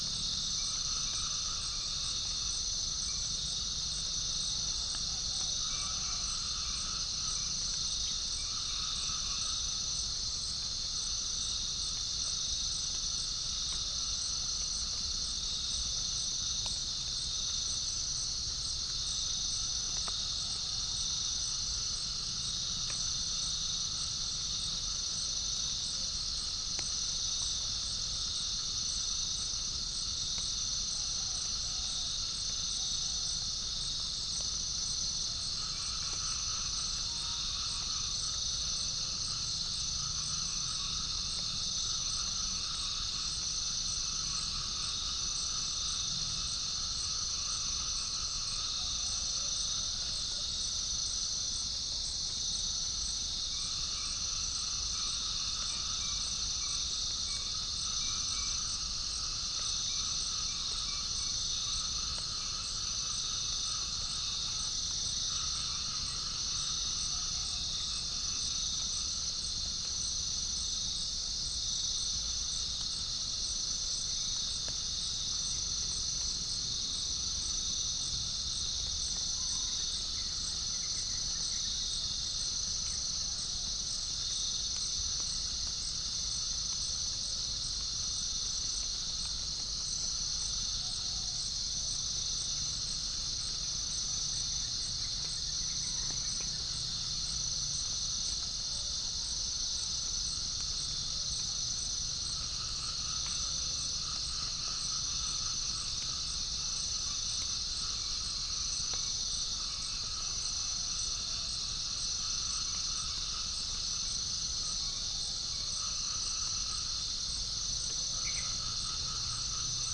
Centropus bengalensis
Gallus gallus
Pycnonotus goiavier
Todiramphus chloris
Prinia familiaris